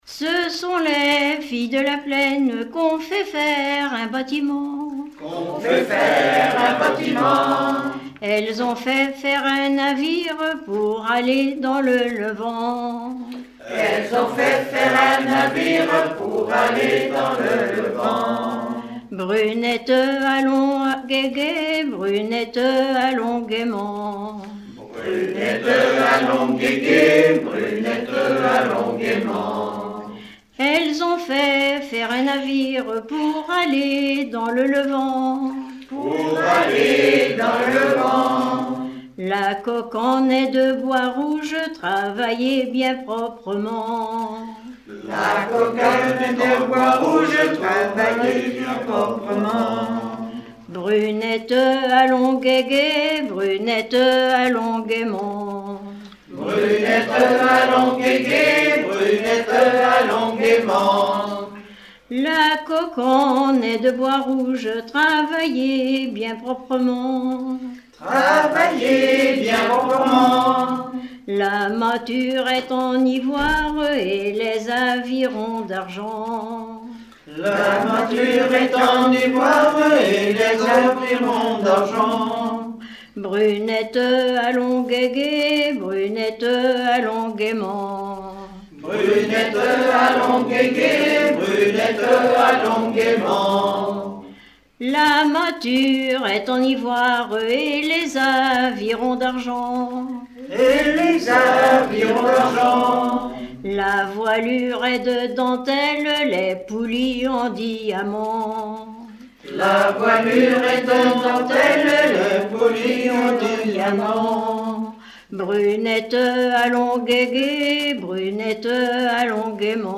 Usage d'après l'informateur circonstance : maritimes
Genre laisse